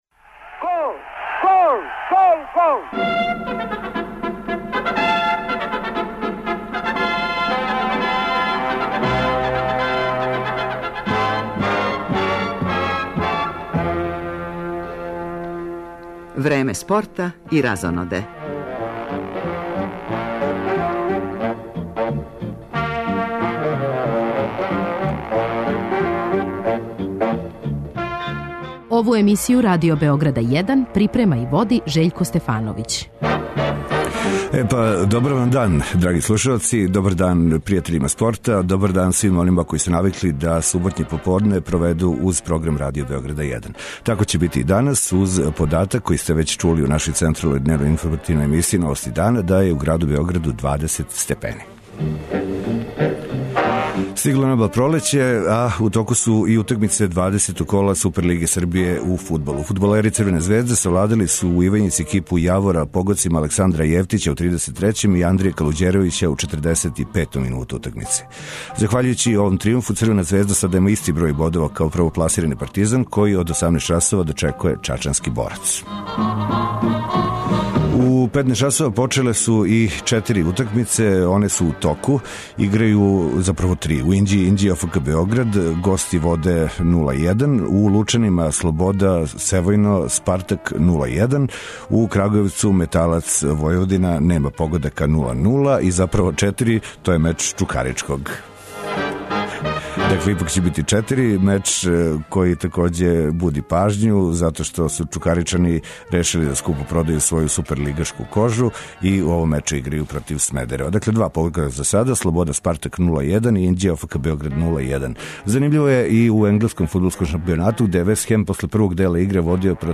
Емисију почињемо извештајем са утакмице Јавор - Црвена Звезда, настављамо комбинованим преносима мечева који су на програму од 15 часова, док се од 18 часова повремено укључујемо у сусрет Партизана и Борца из Чачка.